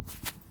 tbd-station-14/Resources/Audio/Effects/Footsteps/grass1.ogg at 0bbe335a3aec216e55e901b9d043de8b0d0c4db1
grass1.ogg